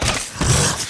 hellhound_walk.wav